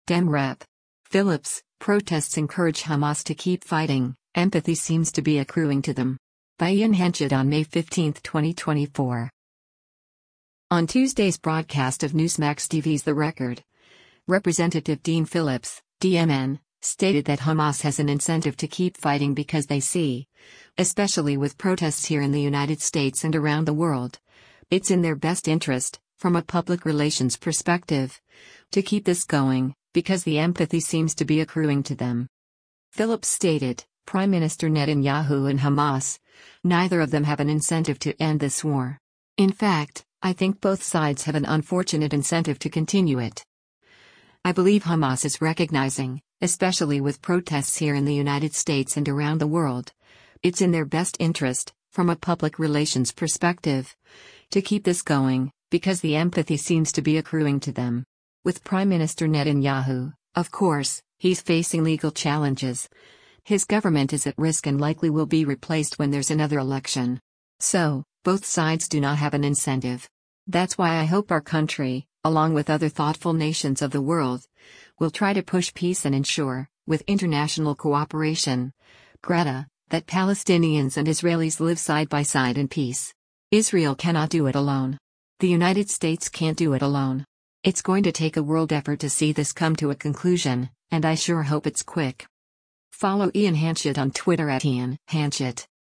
On Tuesday’s broadcast of Newsmax TV’s “The Record,” Rep. Dean Phillips (D-MN) stated that Hamas has an incentive to keep fighting because they see, “especially with protests here in the United States and around the world, it’s in their best interest, from a public relations perspective, to keep this going, because the empathy seems to be accruing to them.”